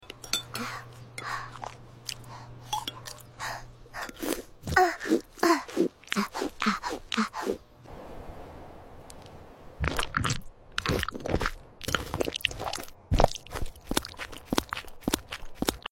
Strawberry & Orange Eating ASMR